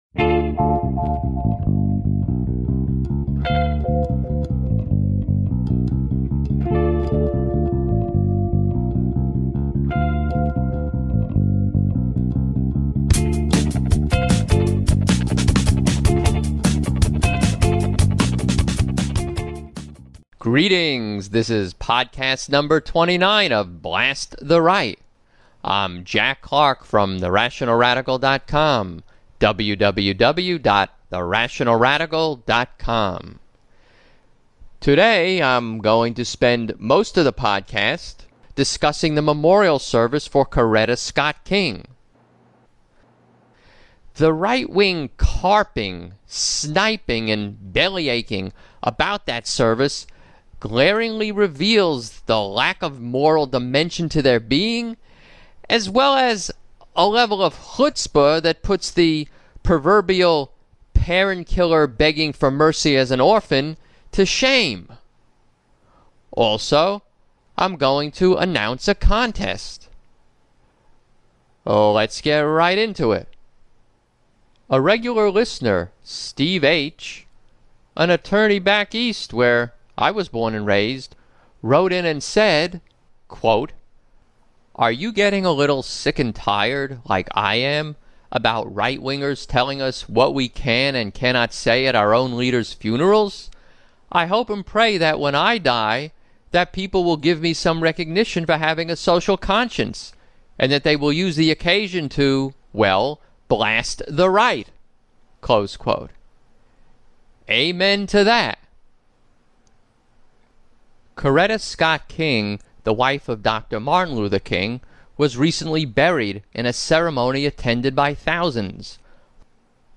29 - Coretta Scott King Funeral "Controversy" Reveals Right-Wingers At Their Worst / Contest The right-wing's phony carping, sniping and belly-aching about the memorial service for Coretta Scott King glaringly reveals the lack of a moral dimension to their being, as well as a level of chutzpah that puts the proverbial parent-killer begging for mercy as an orphan, to shame. Lots of audio clips, one from the service, and several of Sean Hannity being, well, Sean Hannity. Also, I announce a contest for listeners.